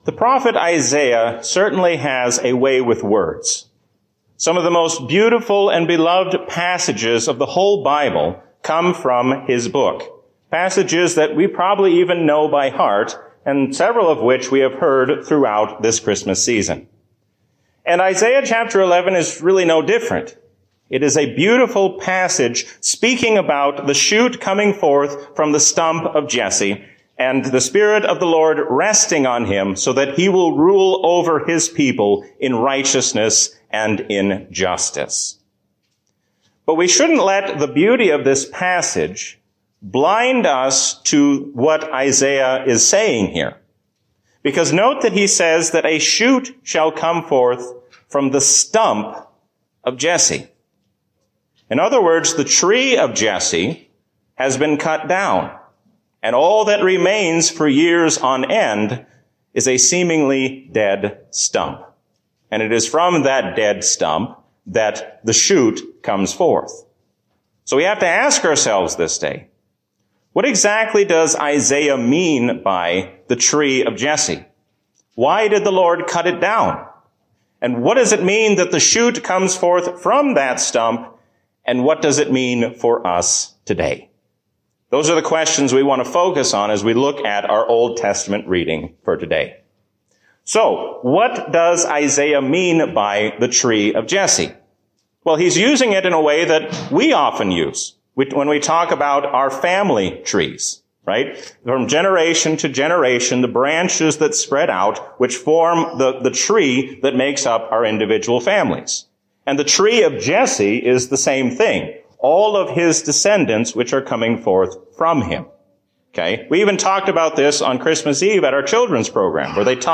A sermon from the season "Trinity 2024."